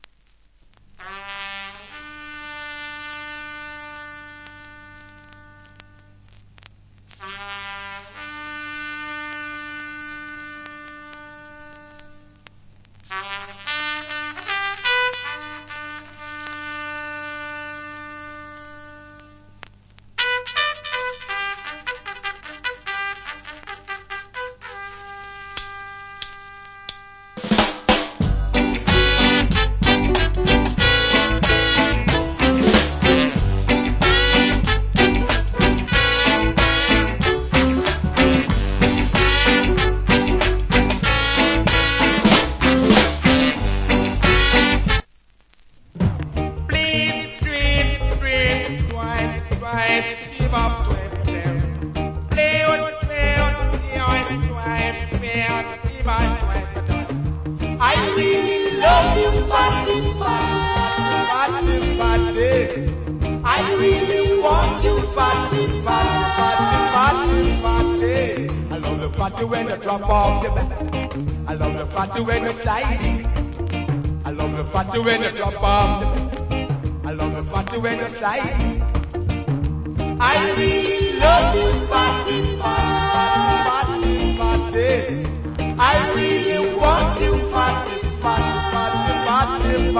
Killer Early Reggae Inst.